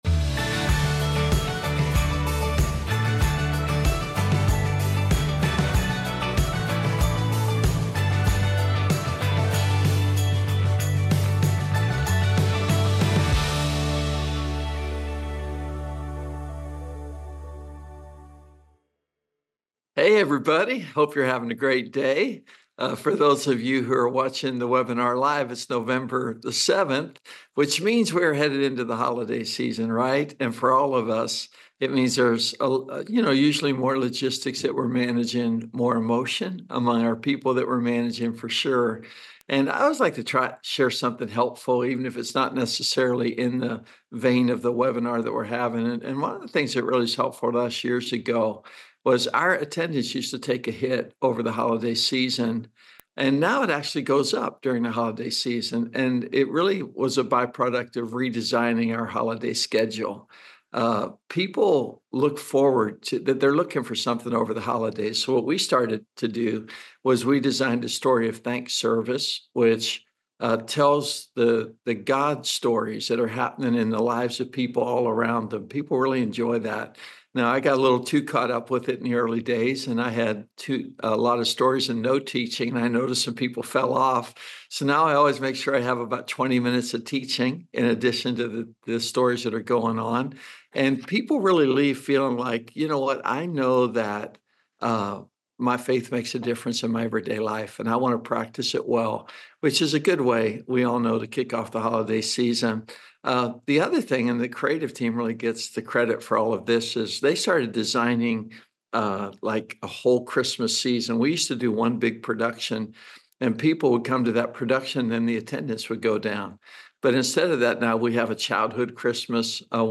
Webinar_Protecting_Your_Church_From_Toxic_Leaders.mp3